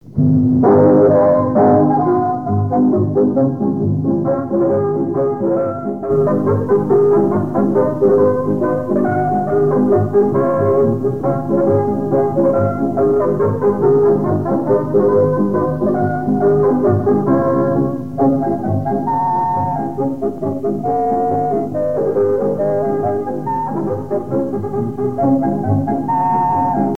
Mémoires et Patrimoines vivants - RaddO est une base de données d'archives iconographiques et sonores.
danse : polka
Pièce musicale inédite